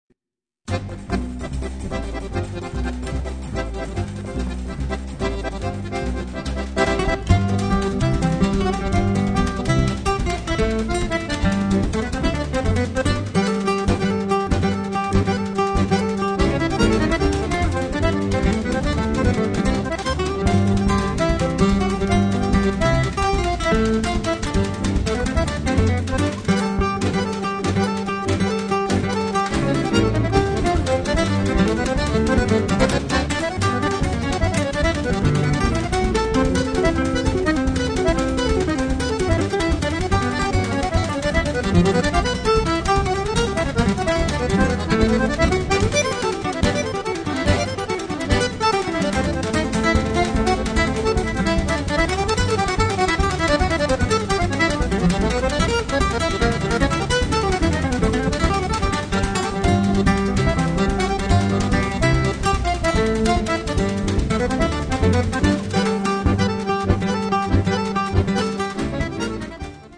chitarra
fisarmonica
contrabbasso
batteria